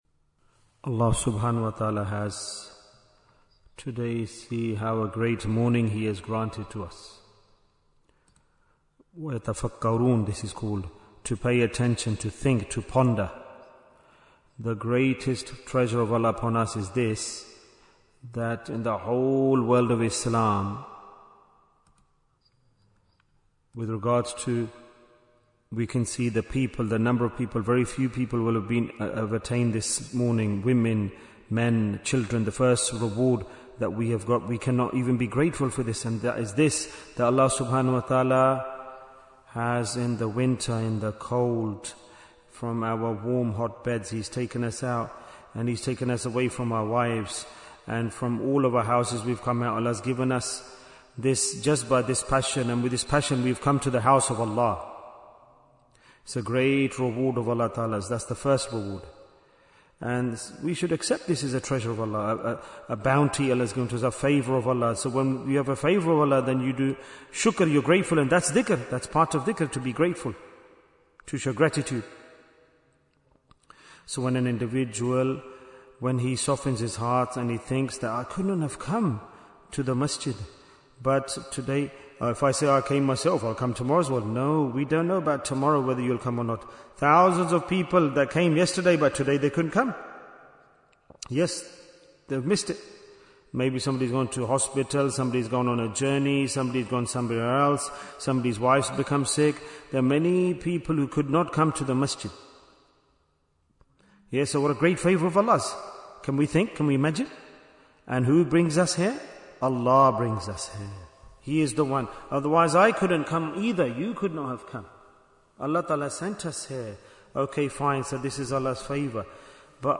Why is Tazkiyyah Important? - Part 6 Bayan, 70 minutes18th January, 2026